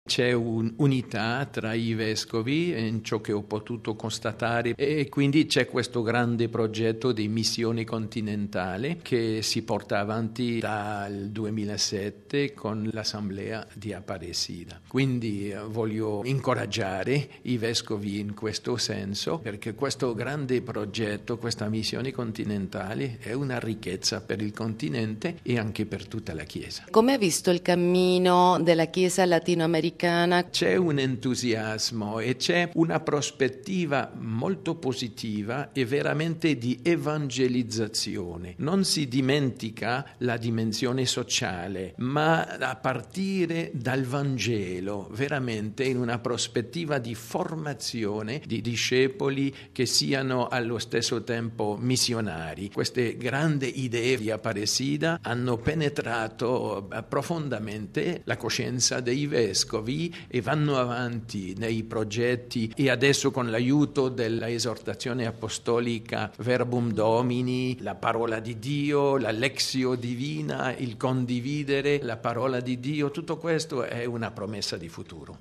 A Montevideo, Messa inaugurale dell’assemblea del Celam. Intervista con il cardinale Ouellet